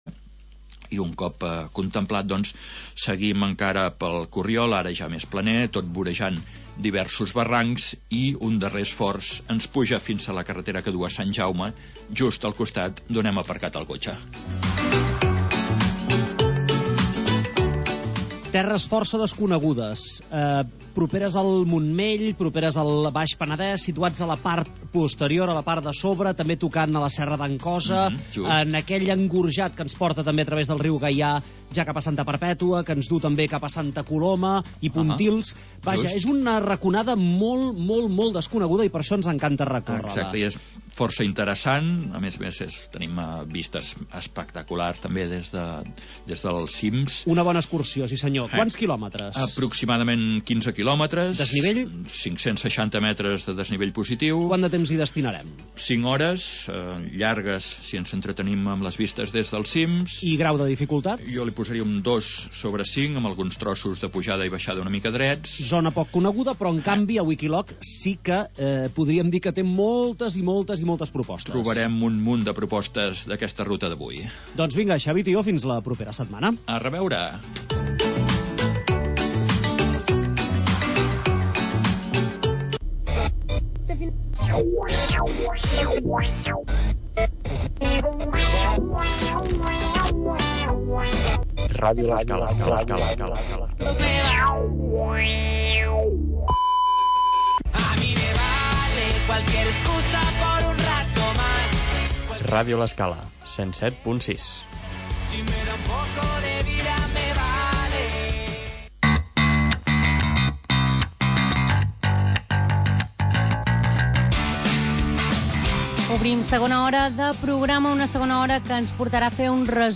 Magazin local d'entreteniment